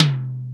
• High Tom Sound D Key 28.wav
Royality free tom drum tuned to the D note. Loudest frequency: 1648Hz
high-tom-sound-d-key-28-9C5.wav